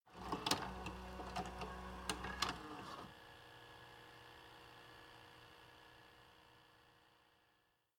VHS_StartPlay.wav